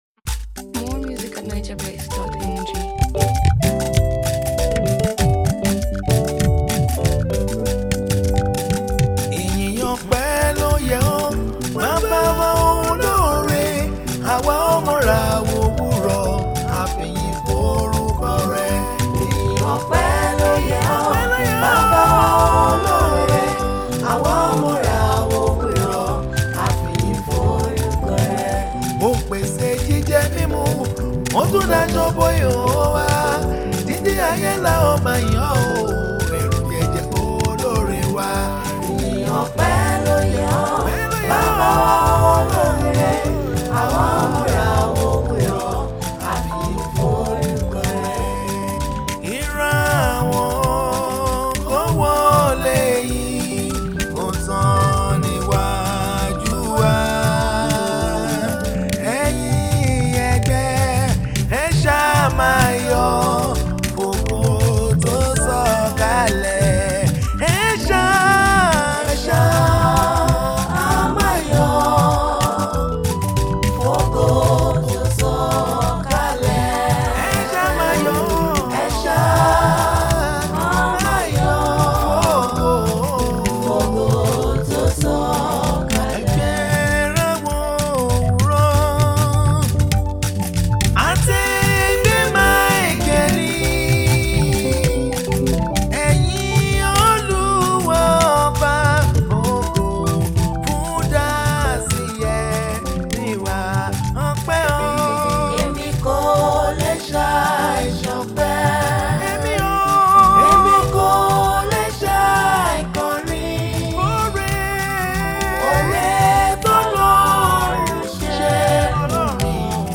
It’s a song of worship, reflection, and spiritual awakening.